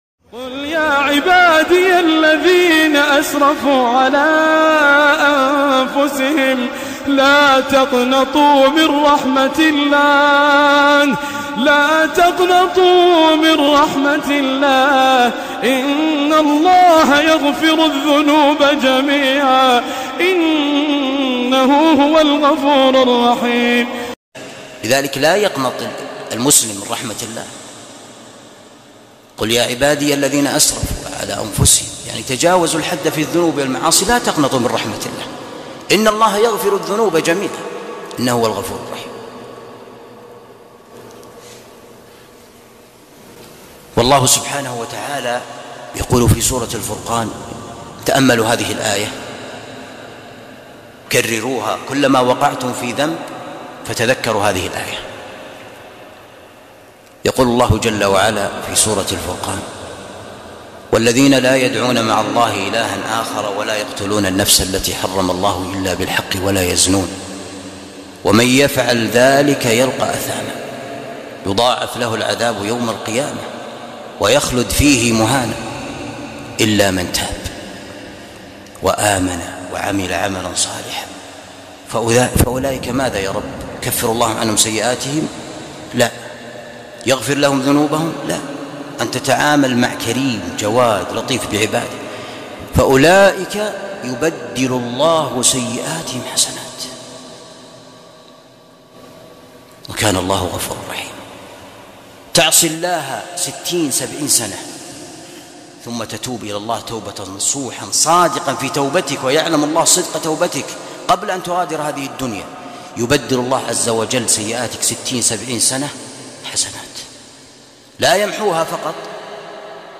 موعظة مؤثرة لا تقنطوا من رحمة الله